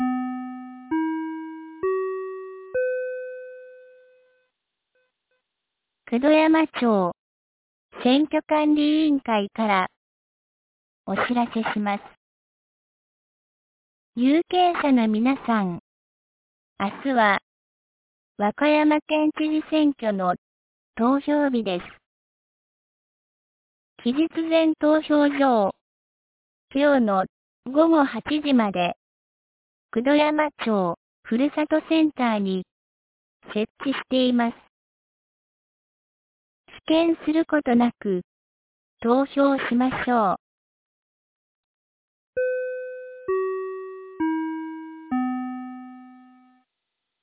2025年05月31日 12時30分に、九度山町より全地区へ放送がありました。